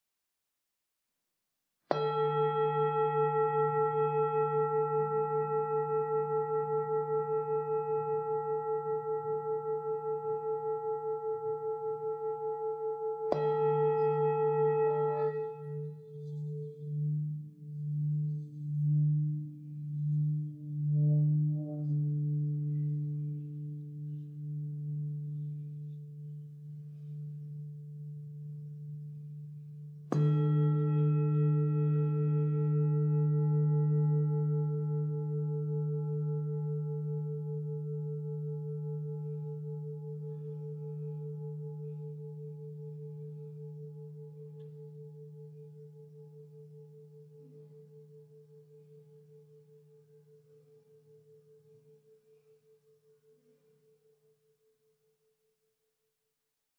Meinl Sonic Energy Energy Therapy Series Singing Bowl - 1400g (SB-E-1400)
Diameter: ~ 9" / ~ 22.9 cmWeight: ~ 48.7 oz / ~ 1380 gMaterial: Special bronze alloyFeature 1: Handcrafted masterpieceFeature 2: Ideal for upper…
The Meinl Sonic Energy Energy Series Singing Bowls feature a reinforcement rim that produces a high tone at first followed by a deep tone afterward,…
When softly tapped or rubbed, Sonic Energy singing bowls release a fascinating, multi-layered, and colorful sound that resonates deeply within the soul. Over a rich fundamental tone, entire waterfalls of singing overtones emerge to float freely in space and unfurl inside the body. Once the sound starts vibrating, it won't stop; even a minute later, a soft reverberation can still be felt.